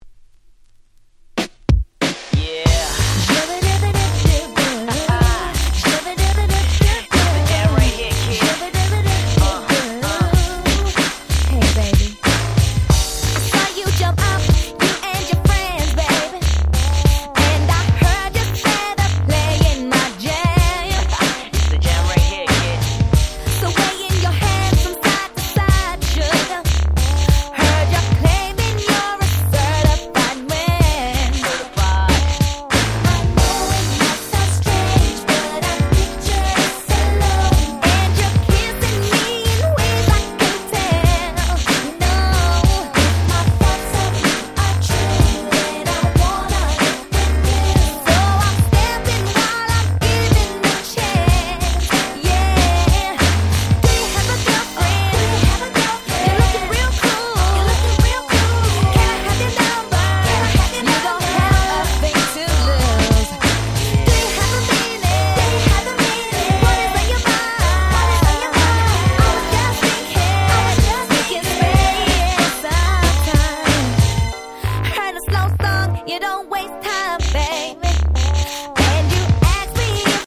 98' Nice EU R&B !!